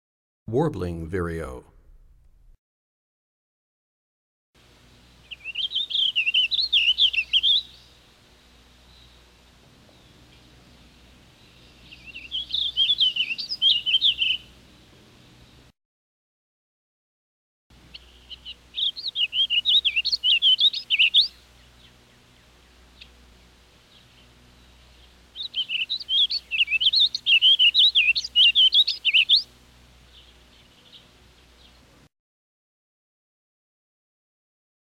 94 Warbling Vireo.mp3